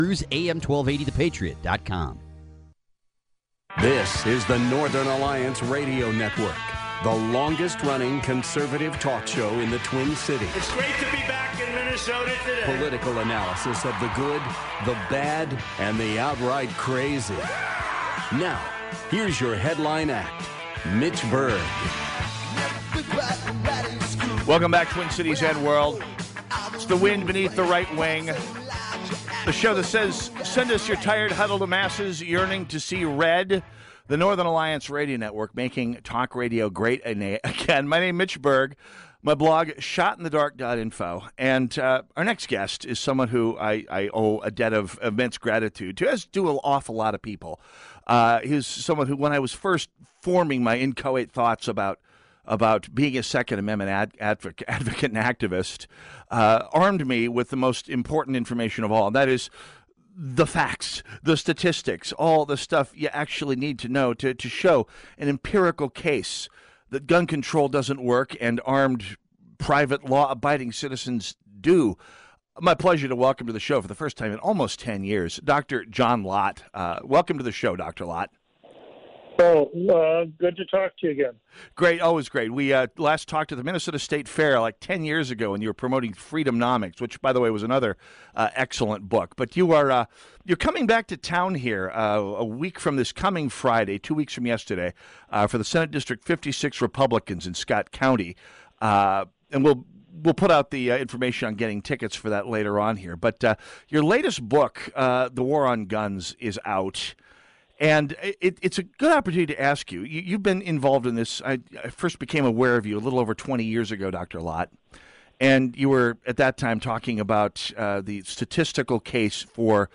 On Minneapolis' The Patriot Radio to discuss Lott's book "The War on Guns" - Crime Prevention Research Center